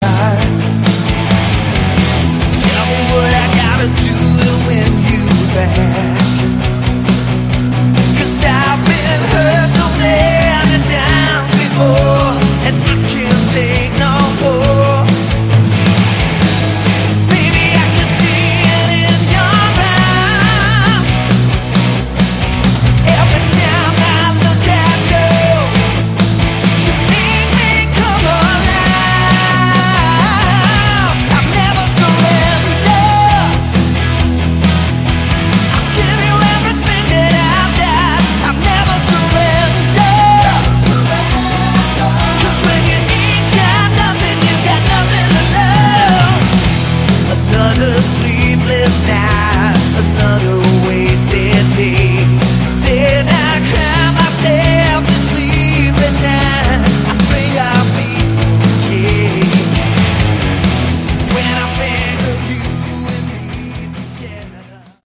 Category: Hard Rock
bass, keyboards
rhythm guitars, keyboards, backing vocals
lead and backing vocals
drums, backing vocals
lead guitar